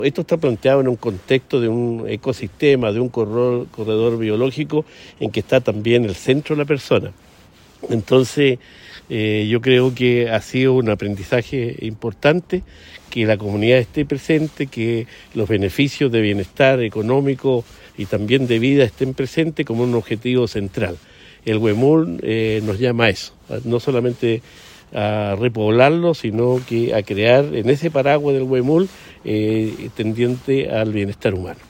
Las declaraciones se dan tras el primer seminario por el repoblamiento del huemul efectuado en la reserva biológica Huilo Huilo, que congregó a representantes de distintas organizaciones públicas y privadas de Chile y Argentina, que han realizado acciones en torno a esa tarea.
Además, en la instancia, Cuvertino dijo que en el repoblamiento del huemul debe considerarse el factor humano, considerándolo un motor de desarrollo de las comunidades.